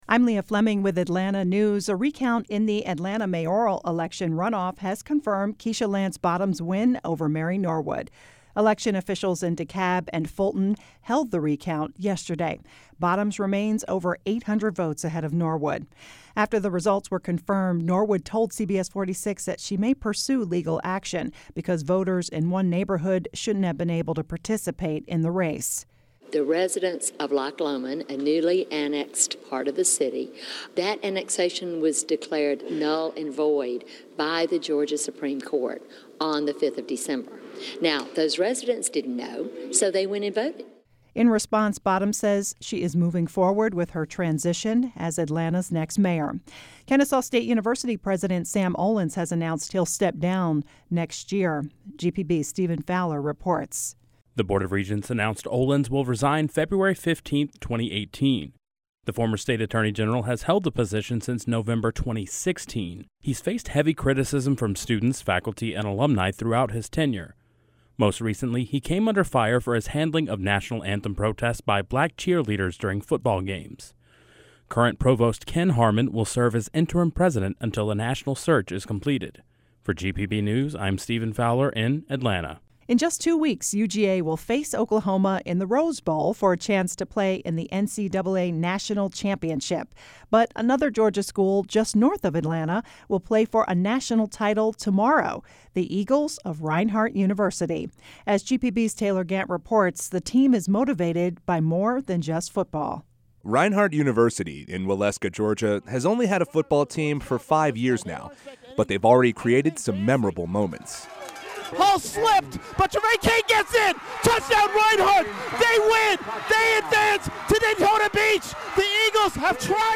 Tags : newscast 88.5 Atlanta Georgia